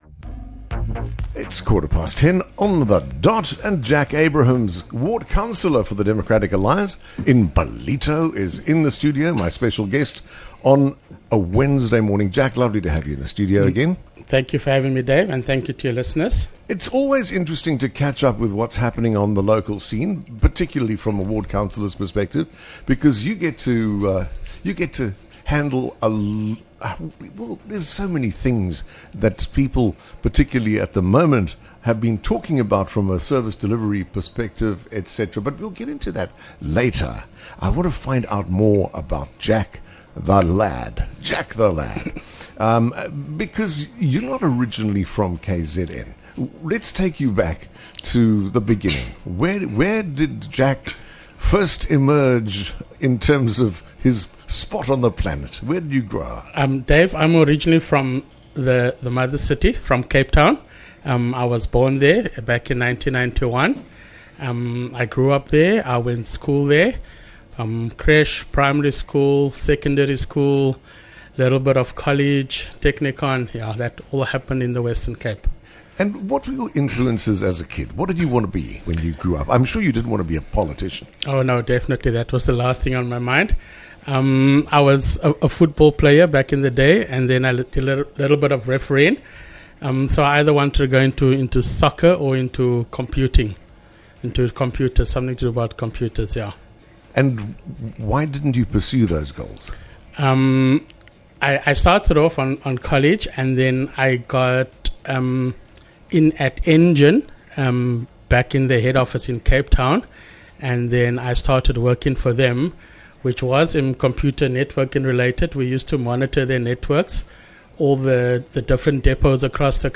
Whether it’s your favourite songs, meaningful milestones, or the moments that shaped you, come and share them live on air.